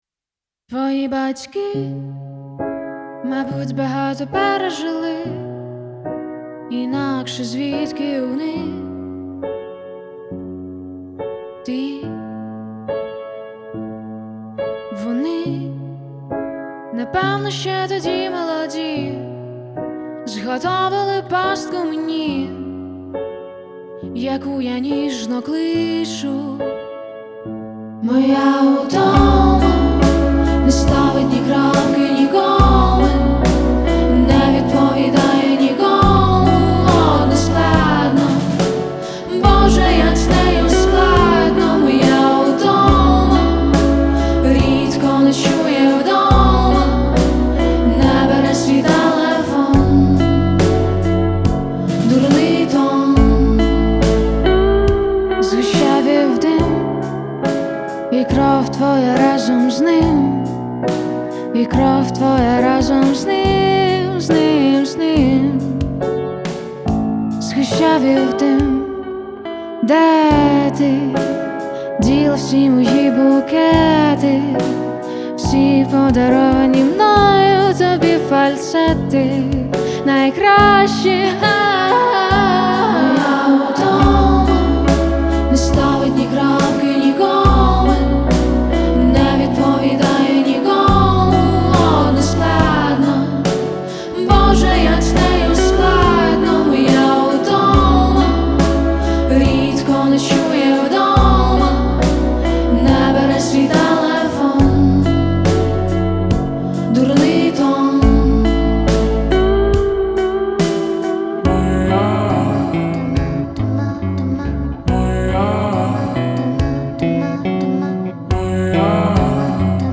• Жанр: R&B, Soul